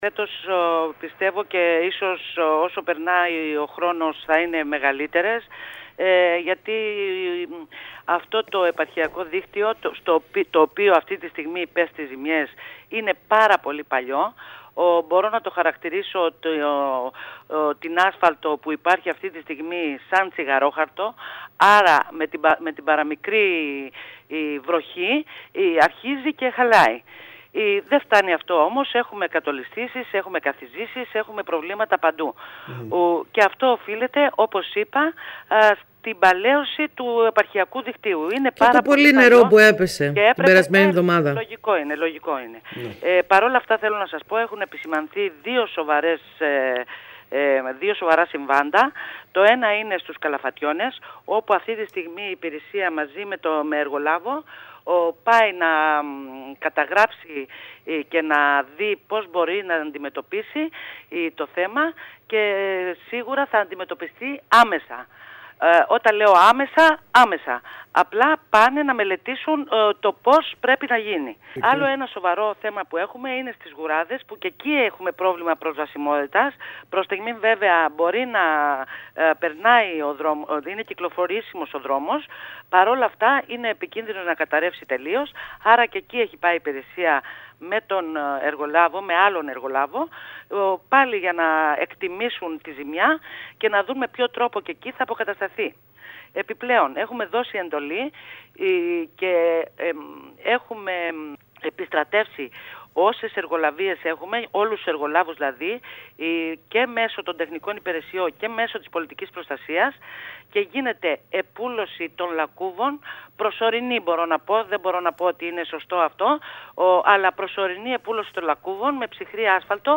Αυτό ανέφερε η Αντιπεριφερειάρχης Νικολέτα Πανδή μιλώντας στο σταθμό μας.